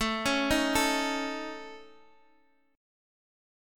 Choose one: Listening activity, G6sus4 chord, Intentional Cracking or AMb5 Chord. AMb5 Chord